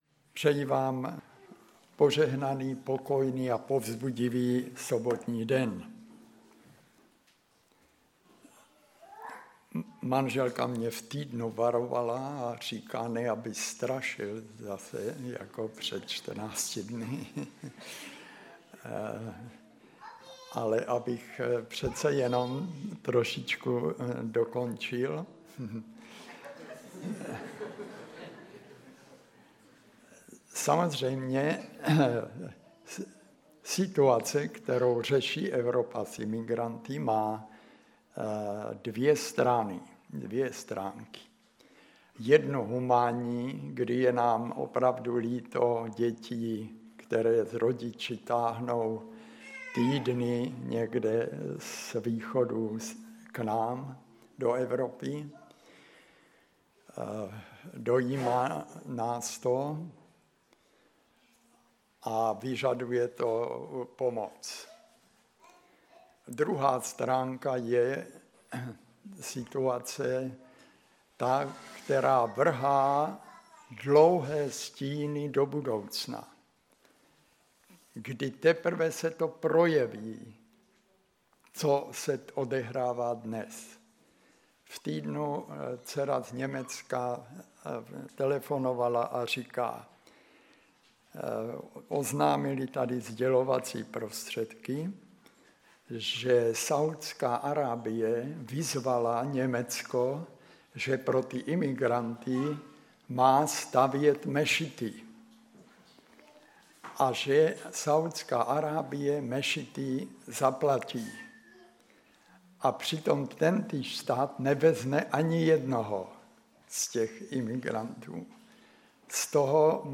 Kázání
ve sboře Ostrava-Radvanice.